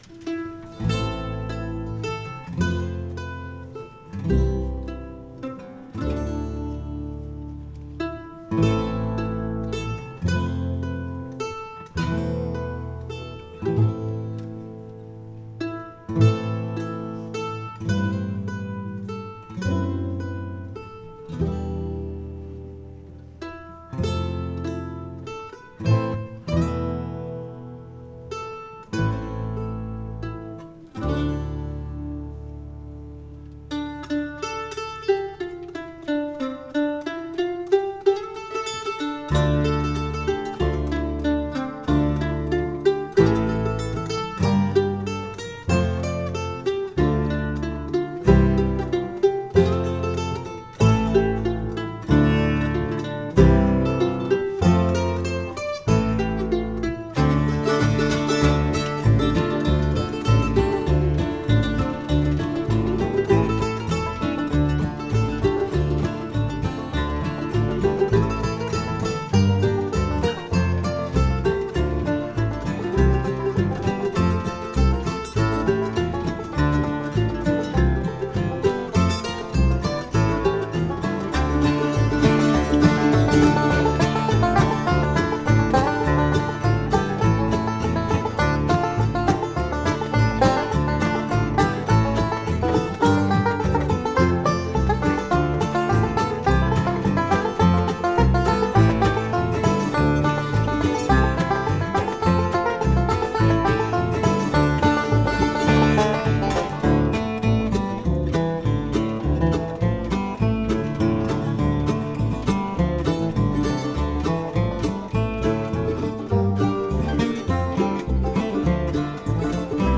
Strong harmonies featuring a capella vocals.